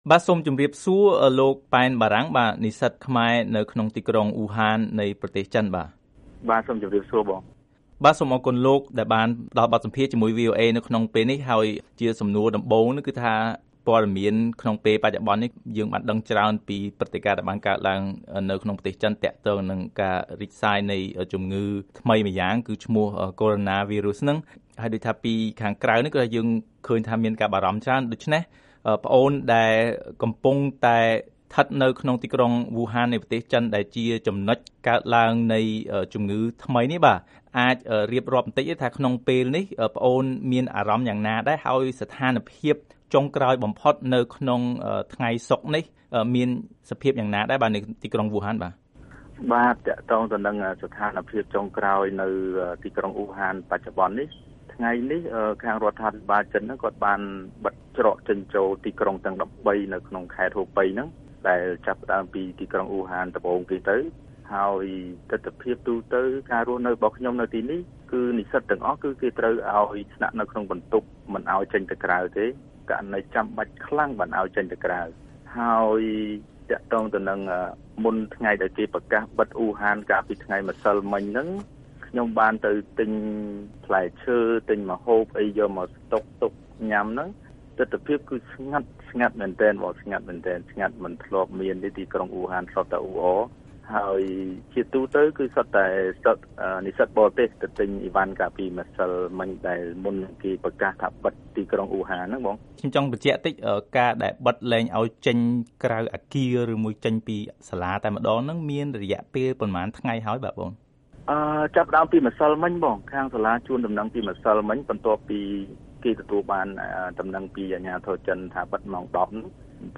បទសម្ភាសន៍ VOA៖ និស្សិតខ្មែរនៅអ៊ូហានបារម្ភចំពោះមេរោគថ្មីខណៈត្រូវជាប់ក្នុងទីក្រុងនេះក្នុងអំឡុងបុណ្យចូលឆ្នាំចិន